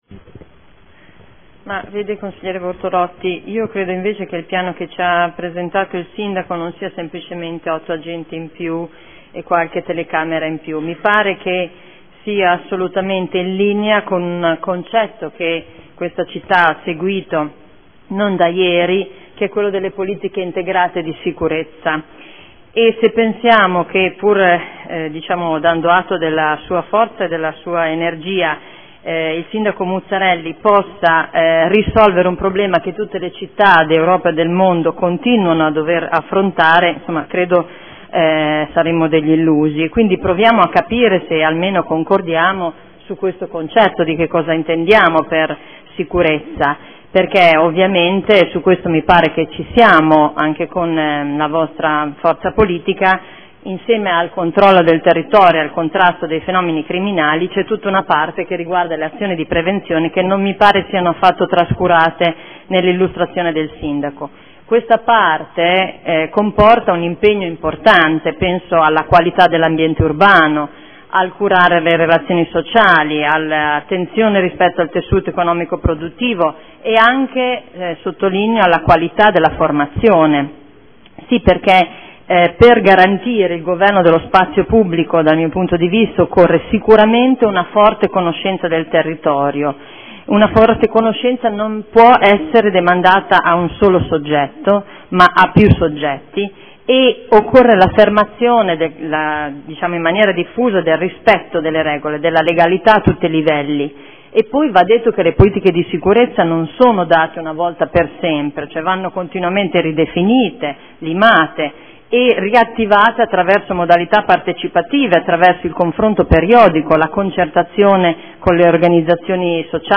Seduta del 9/10/2014 Dibattito Sicurezza.